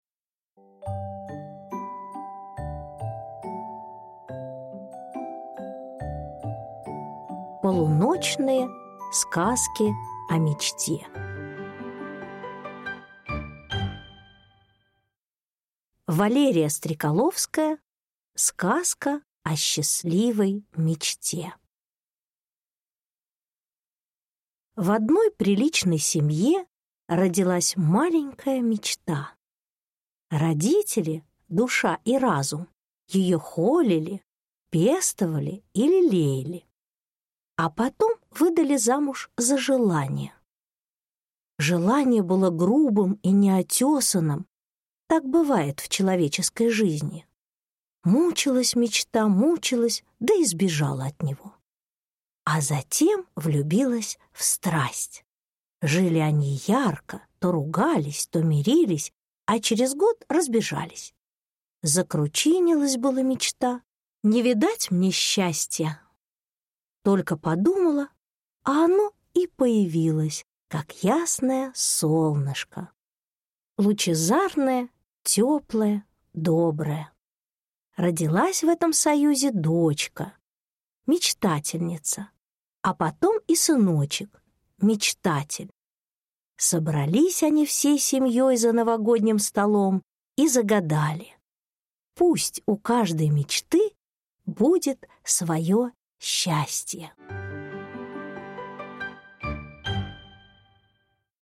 Аудиокнига Полуночные сказки о Мечте.